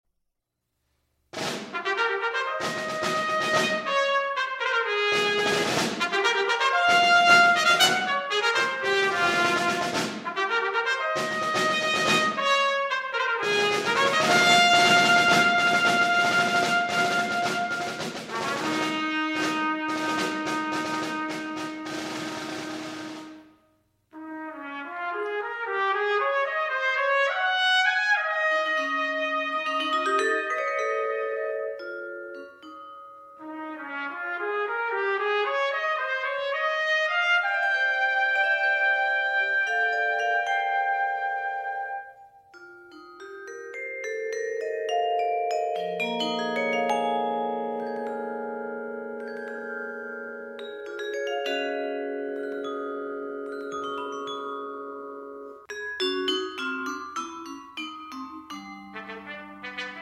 trumpets